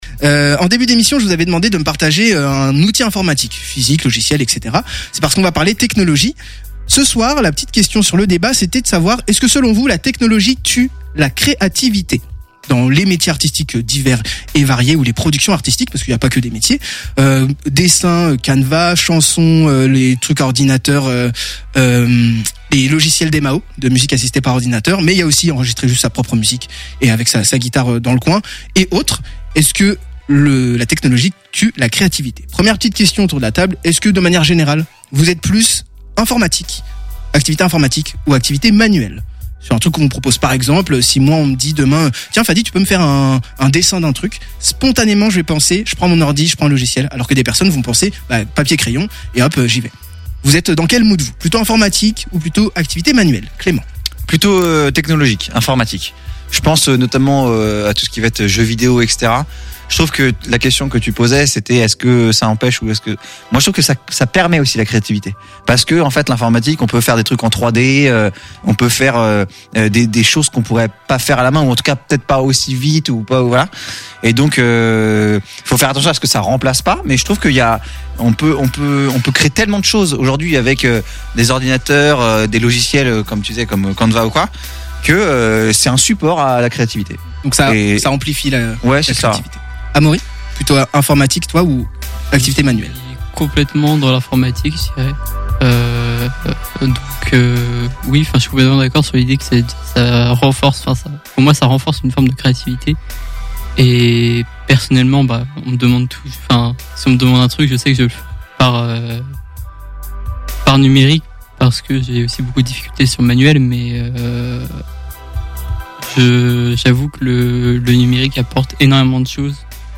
Débat - G!